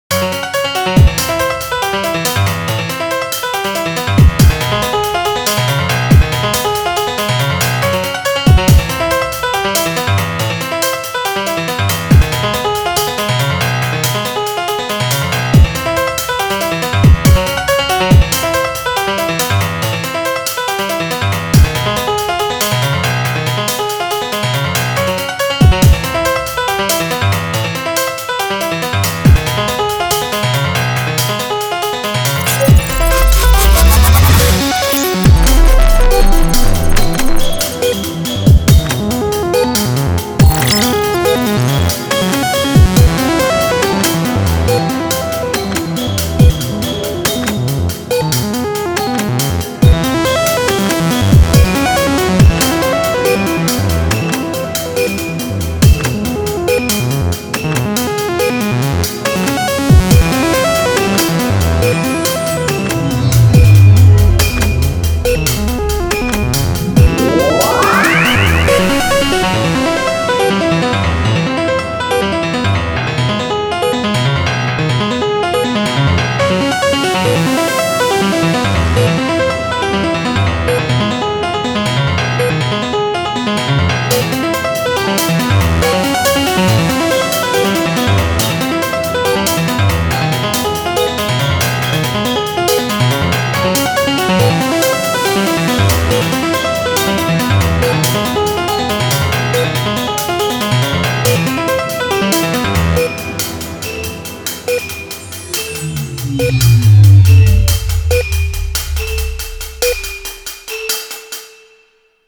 EDM
ピアノ
シンセ
マリンバ
打楽器
暗い
シリアス
ミステリアス
怪しい
激しい